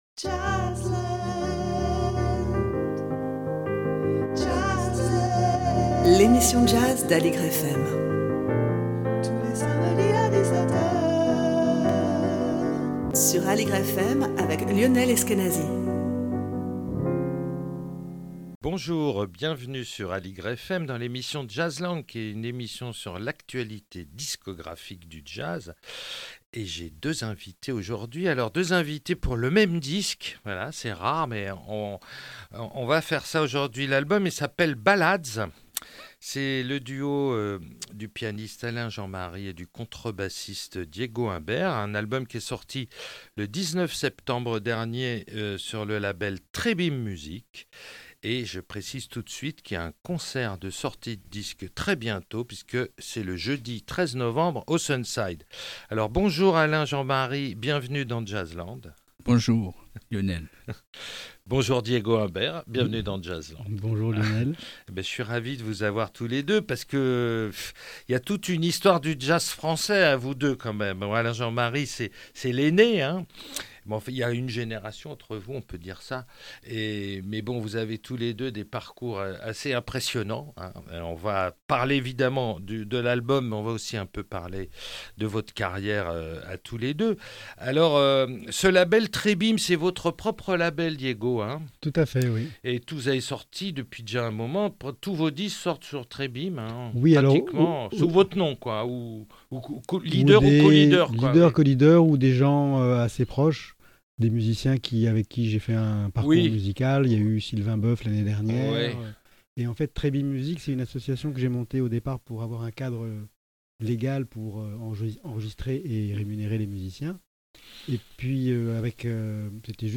sur l'actualité du jazz avec deux invités